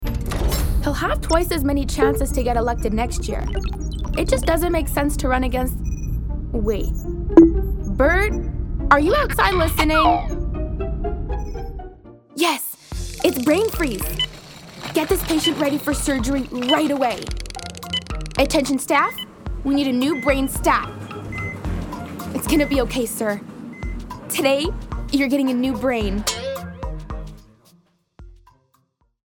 Animation (1) - ANG